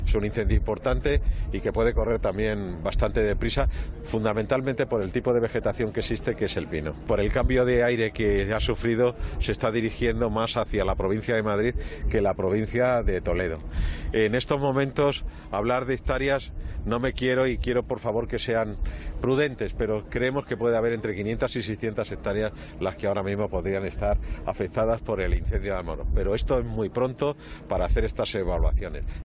Javier Nicolás, delegado de la Junta de Castilla-La Mancha en Toledo, asegura que el fuego se dirige a Madrid